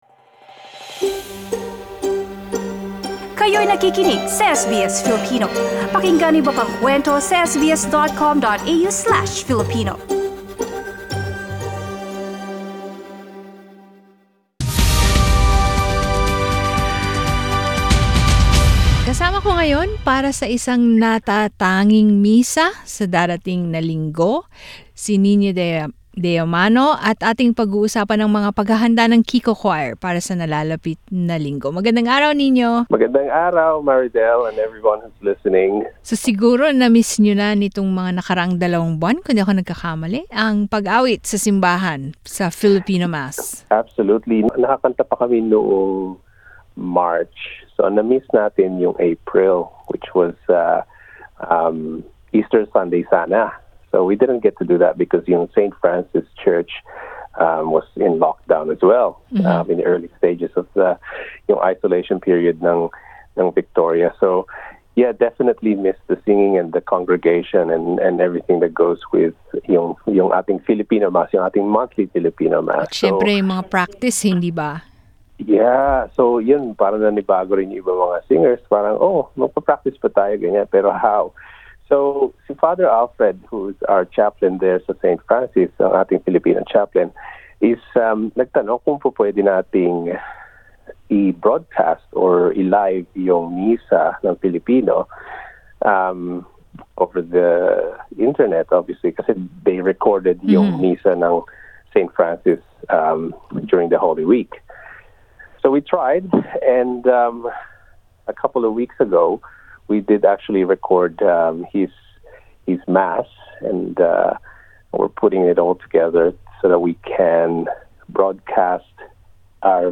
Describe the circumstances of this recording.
This Sunday, 10 May the Filipino Mass at St Francis Church has been pre-recorded The Kiko Choir will be part of the pre-recorded mass